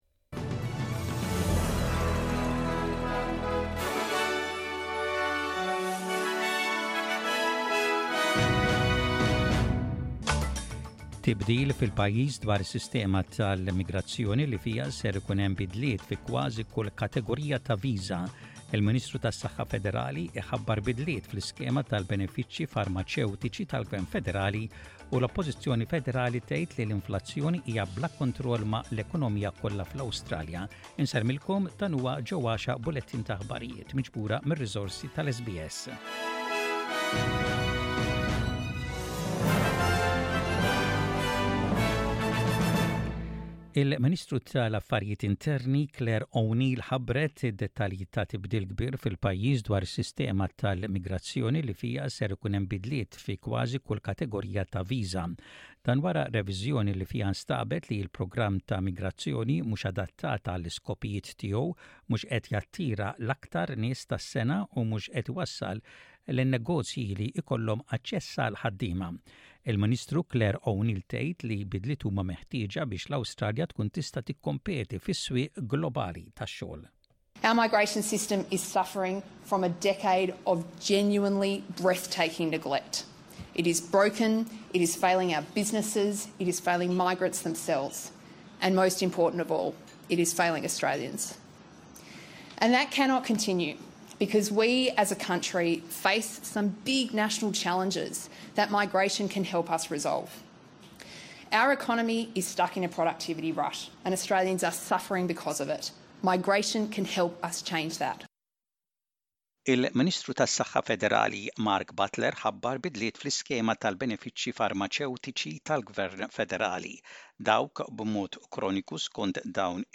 SBS Radio | Maltese News: 28/04/23